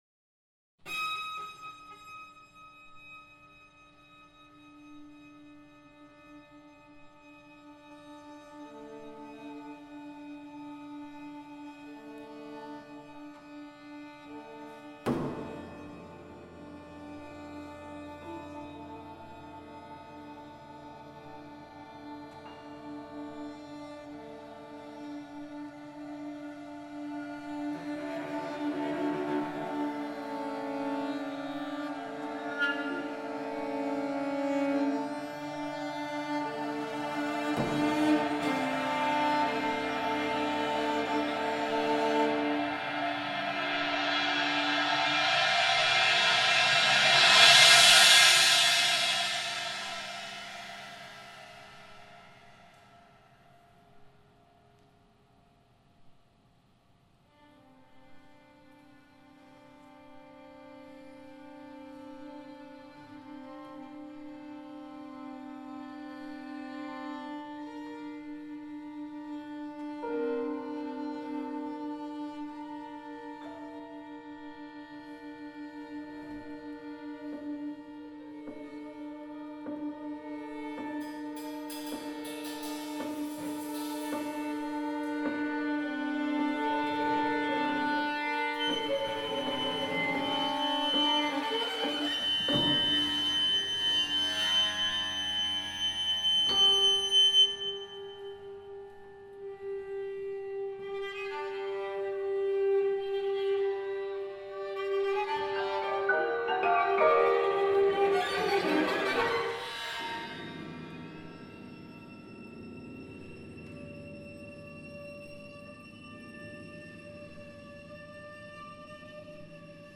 Cello
Clarinet
Percussion
Piano
Piccolo
Violin
Instrumental Ensemble
Chamber